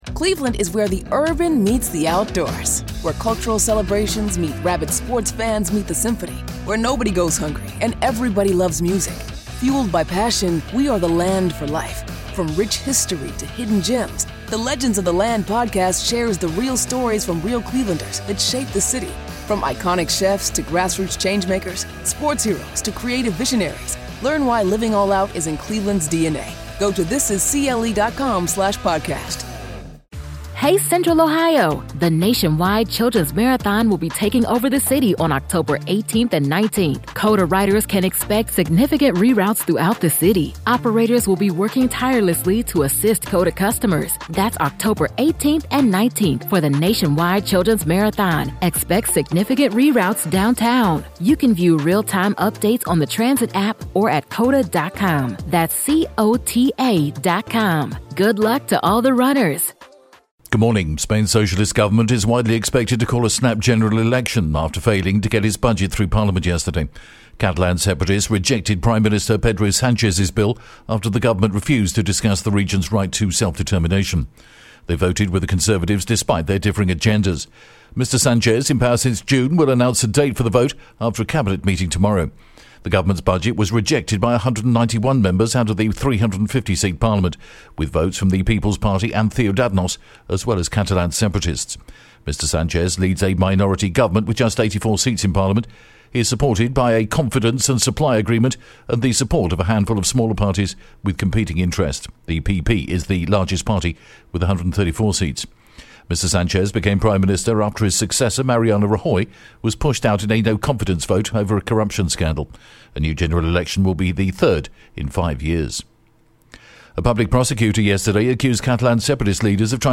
The latest Spanish News Headlines in English: February 14th